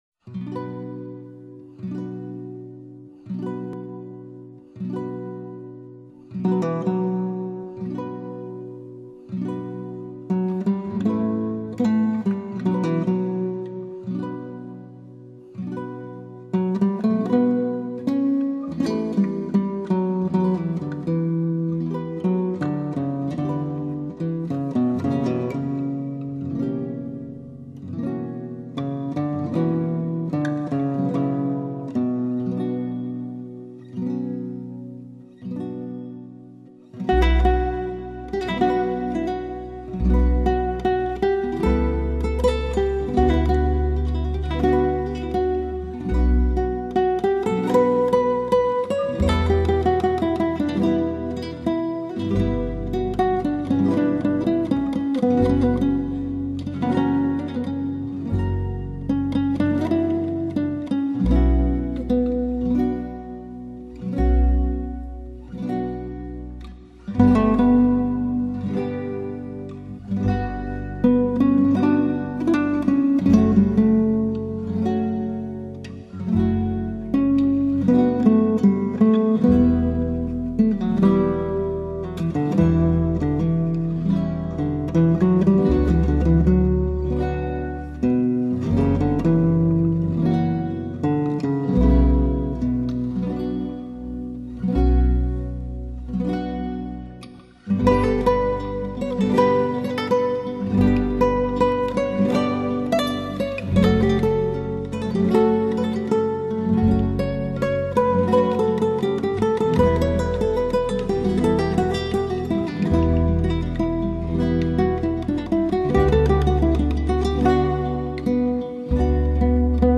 版本：吉他
系为吉他和管弦乐而作，其余的则是吉他独奏作品。
但音乐品质一以贯之的优良.